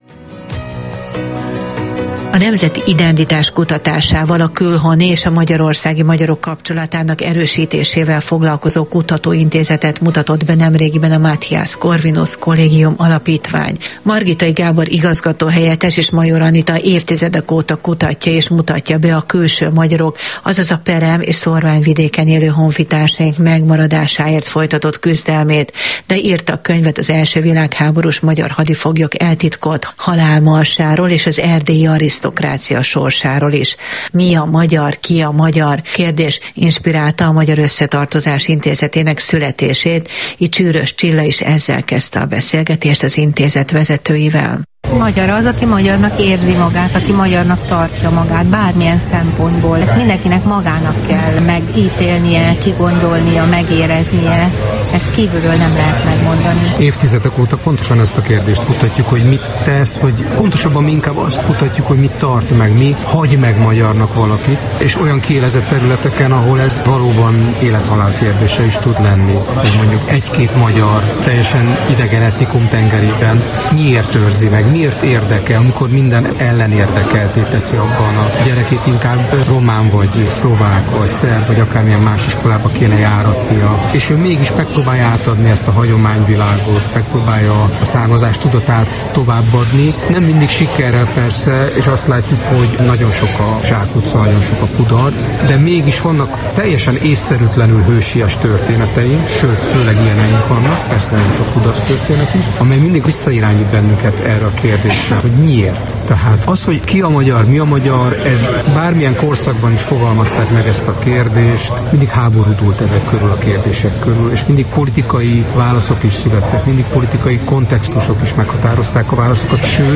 a Kossuth Rádió műsorában